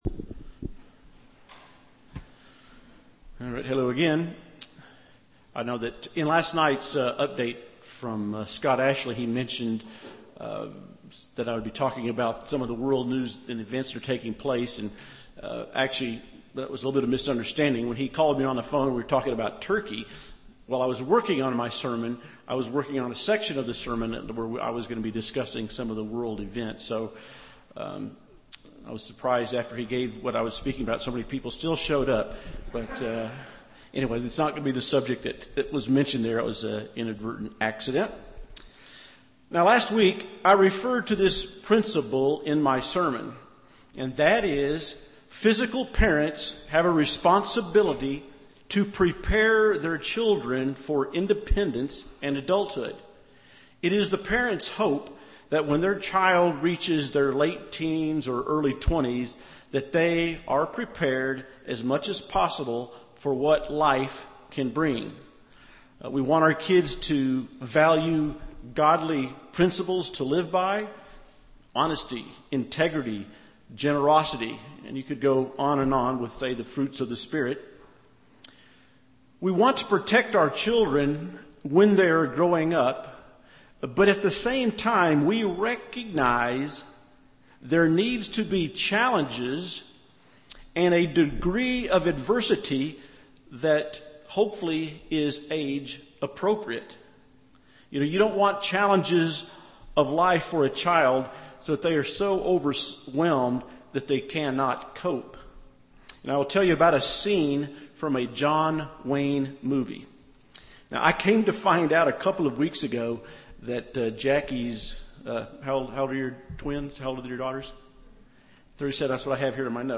Sermons
Given in Loveland, CO